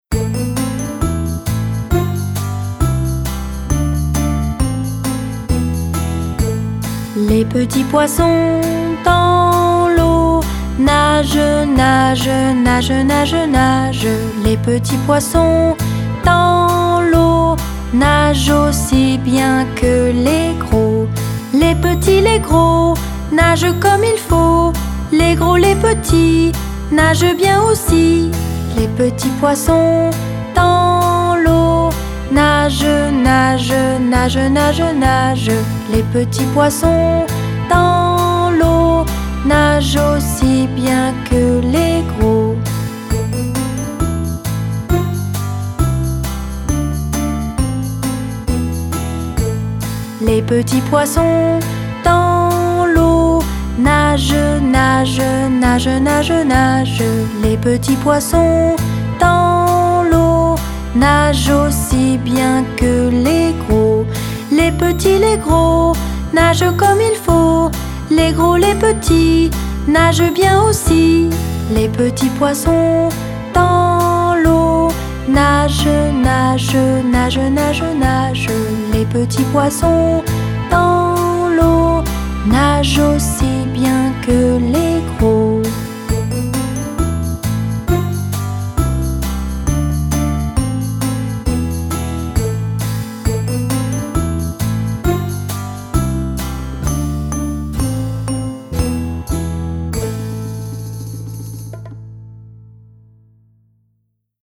chanson française traditionnelle bien connue des enfants